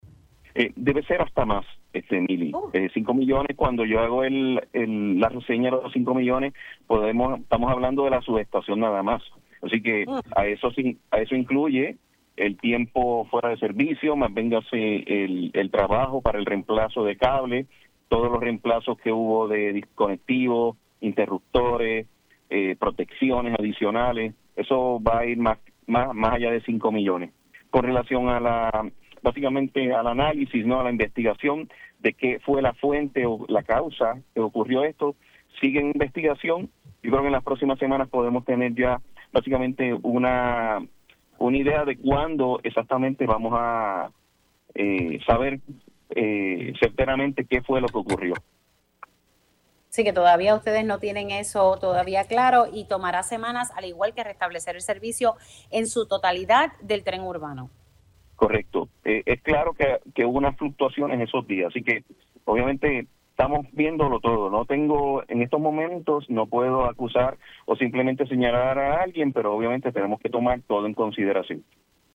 El director ejecutivo de la Autoridad de Transporte Integrado (ATI), Josué Menéndez reconoció en Pega’os en la Mañana que una fluctuación de voltaje pudo haber sido la causa de la explosión en la subestación del Tren Urbano, ocurrida el pasado miércoles, 14 de mayo.